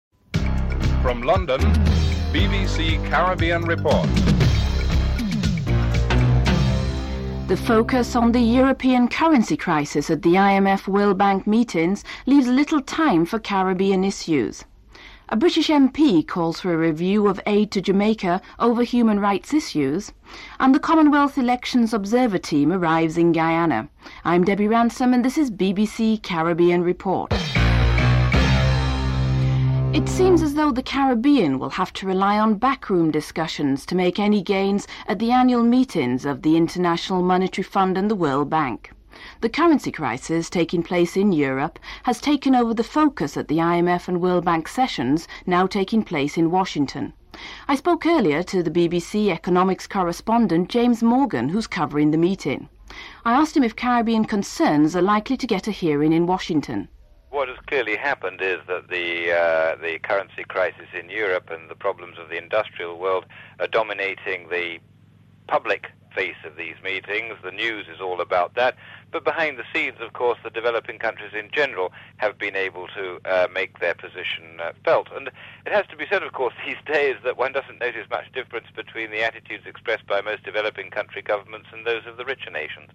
1. Headlines (00:00-00:28)
6. Speaking at a campaign in the run up to Trinidad’s local elections, Prime Minister Patrick Manning reassures supporters that his plan for economic union with Guyana and Barbados is very much alive. (10:55-12:04)